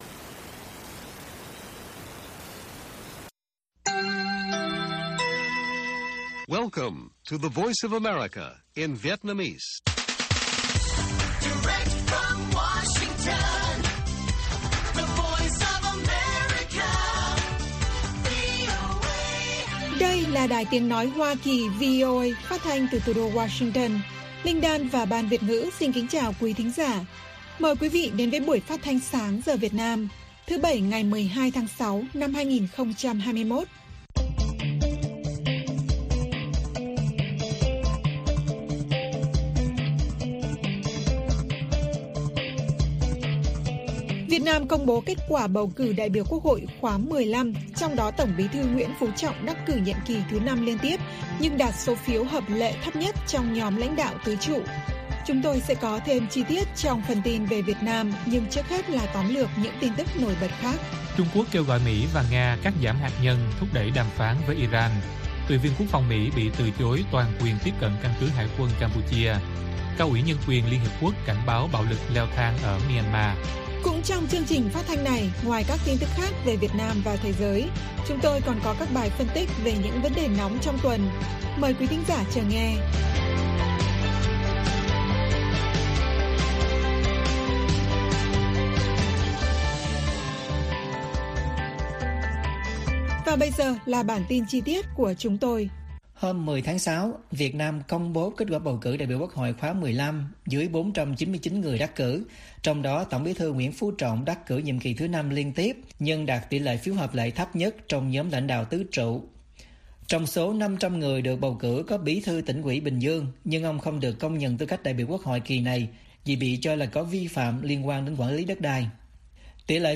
Bản tin VOA ngày 12/6/2021